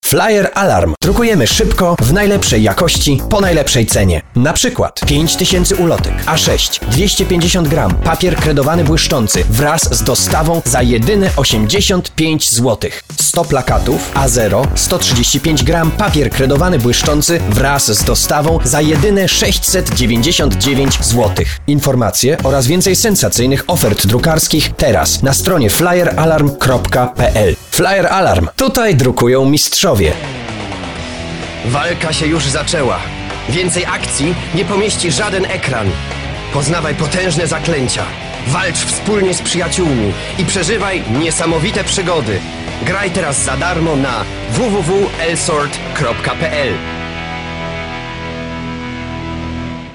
Flexible Tonfärbung.
Sprechprobe: Werbung (Muttersprache):